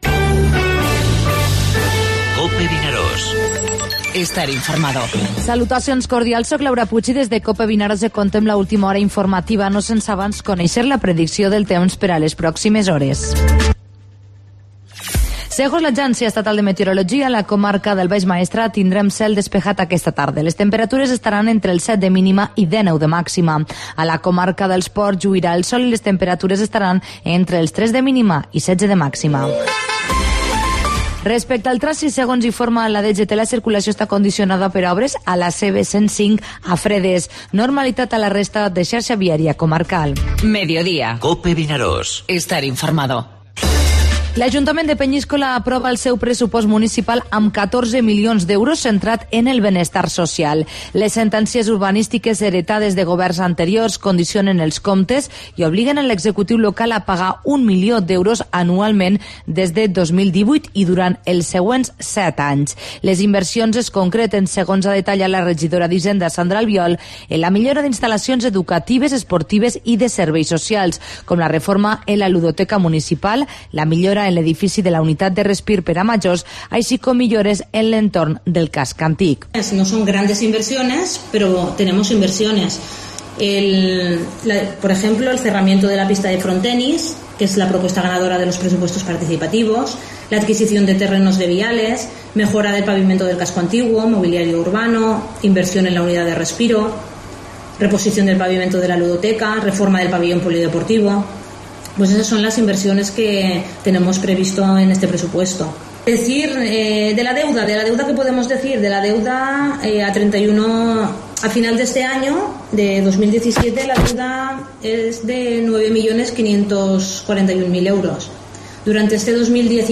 Informatiu Mediodía COPE al Maestrat (17/11/2017)